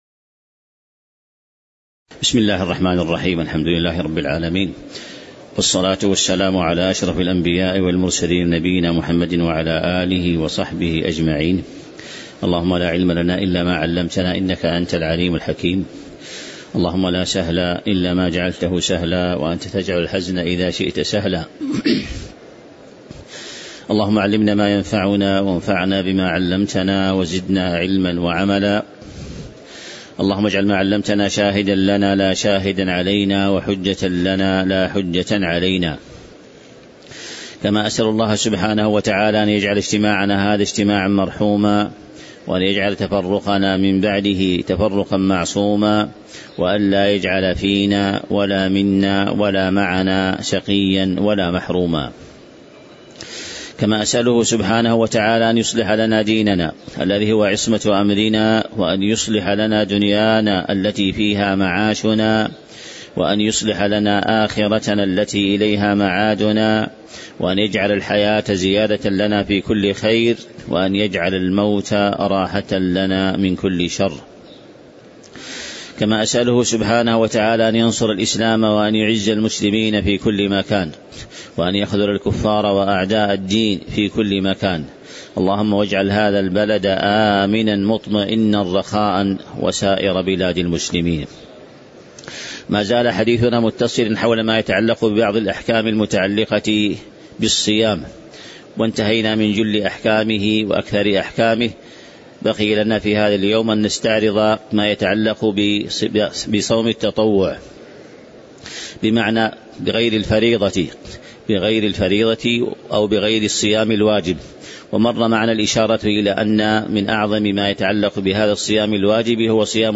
تاريخ النشر ١٨ شعبان ١٤٤٤ هـ المكان: المسجد النبوي الشيخ